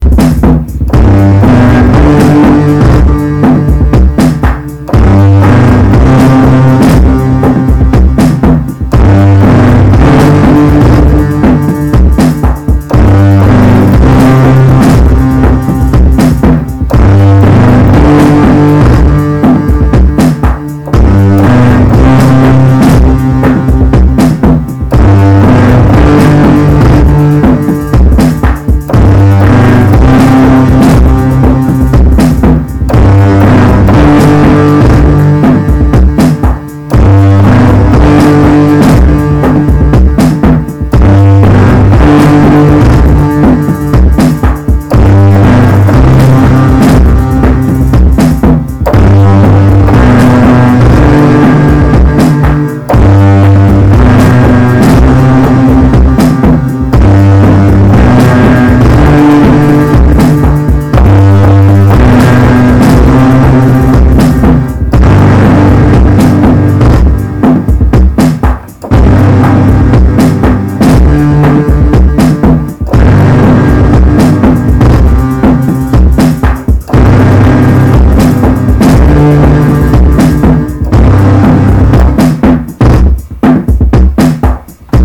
A rough drum pattern I've been messing with, in Strudel.
I plugged in the kid's old synth and got that talking to VMPK.
Recorded in Audacity, I could see the levels were too high to begin with
Kid tells me the audio sounds "deep fried" 🤣